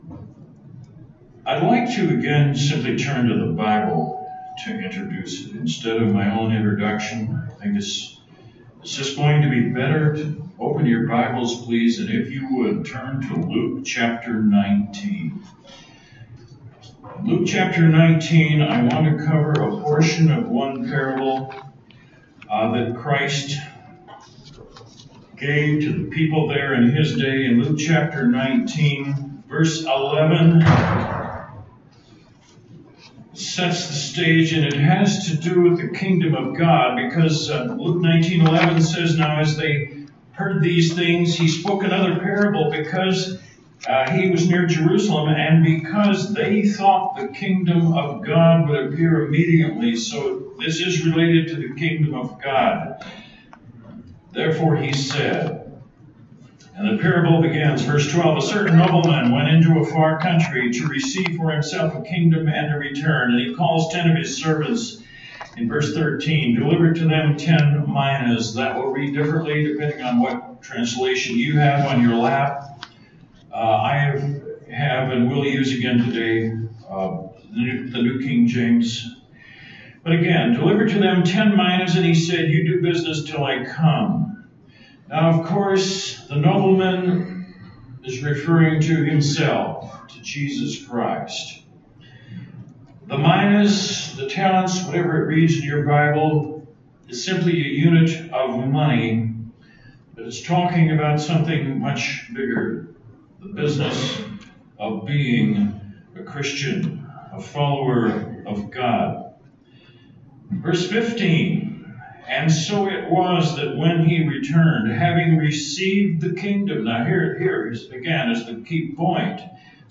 This sermon was given at the Drumheller, Alberta 2018 Feast site.